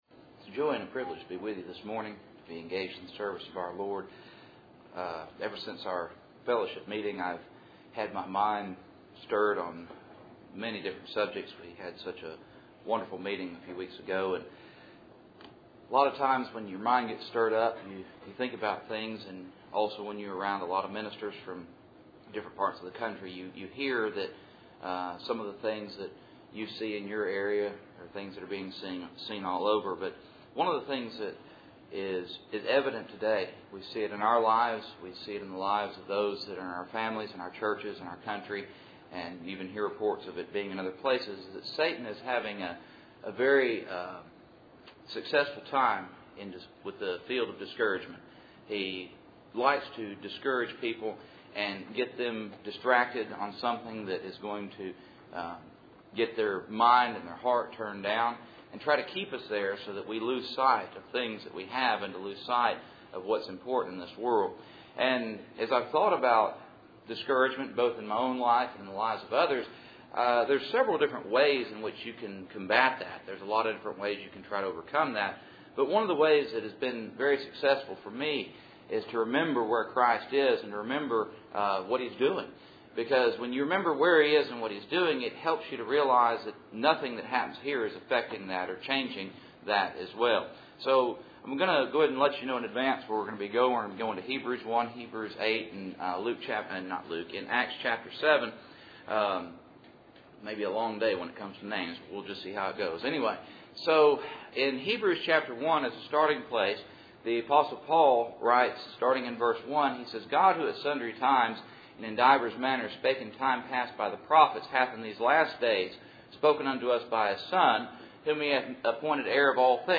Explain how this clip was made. Service Type: Cool Springs PBC 1st Saturday